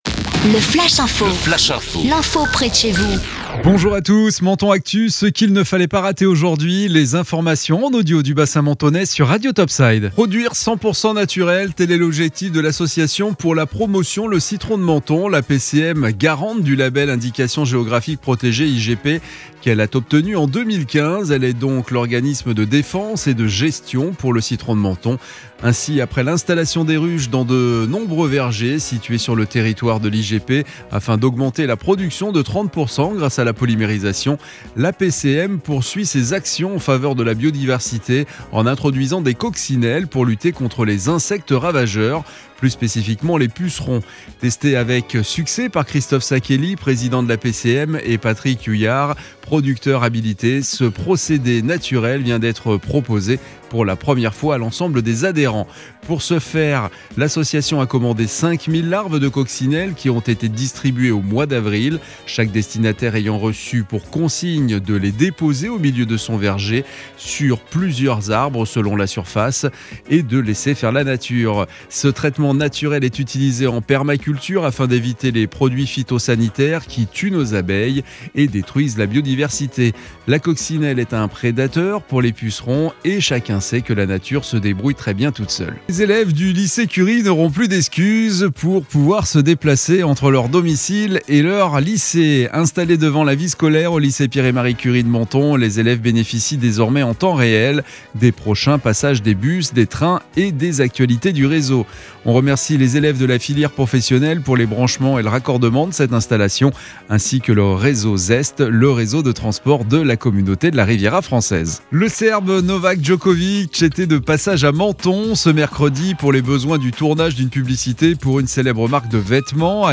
Menton Actu - Le flash info du jeudi 8 avril 2021